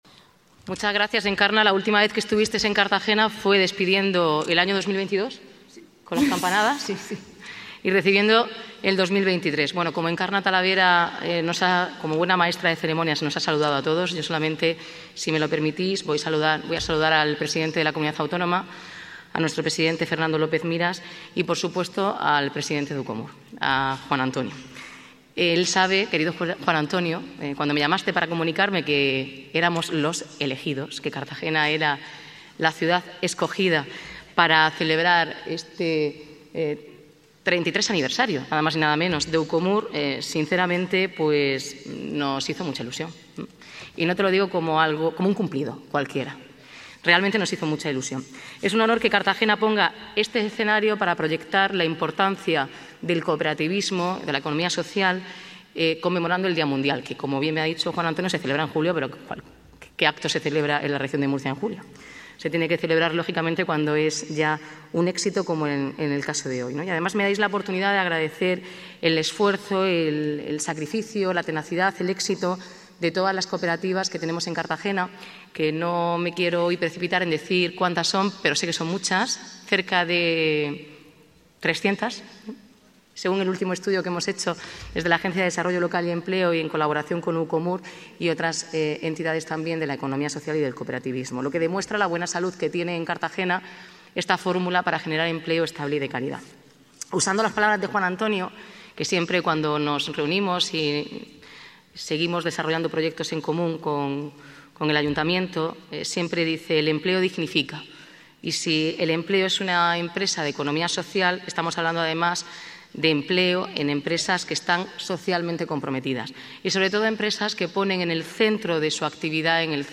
Enlace a Intervención de la Alcaldesa en la gala de premios de Ucomur